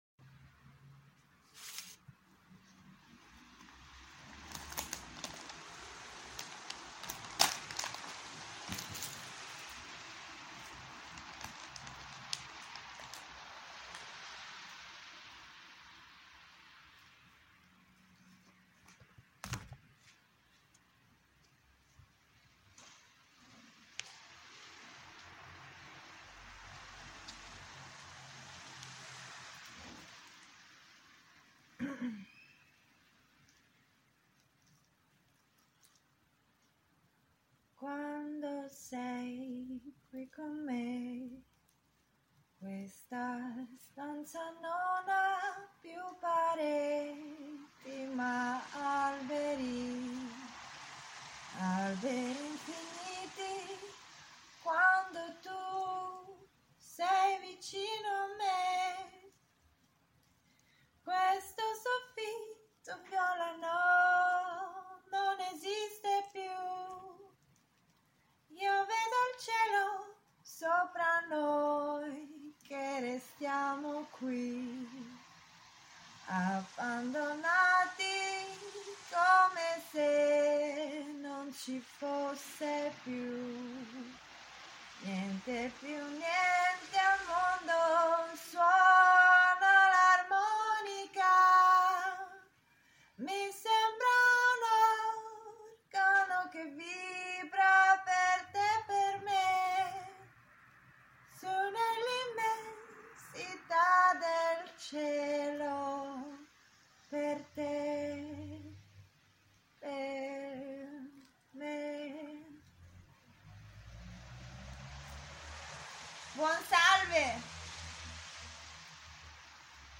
Singing on a rainy night in Alessandria
Alessandria, Italy lockdown sound